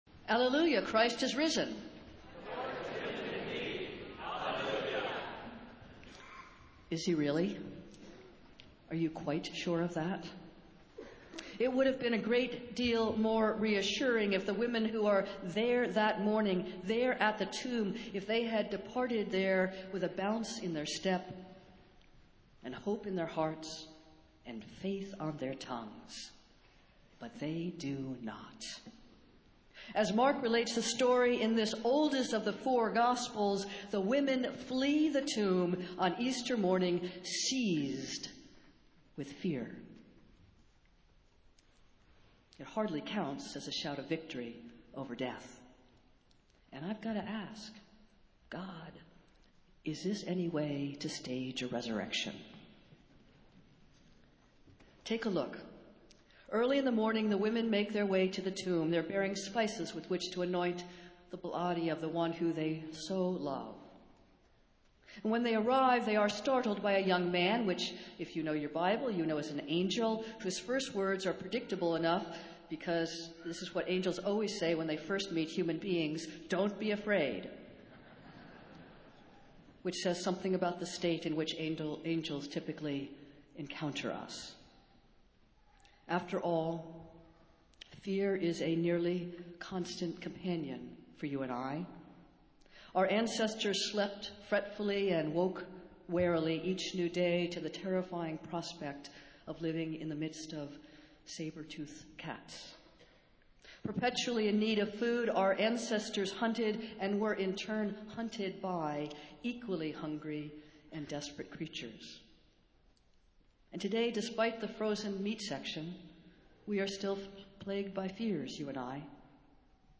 Festival Worship - Easter Sunday, 9 o'clock